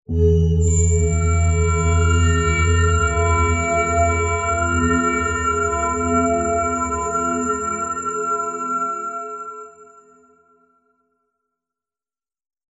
Tonos EFECTO DE SONIDO DE AMBIENTE de LUCES MISTERIOSAS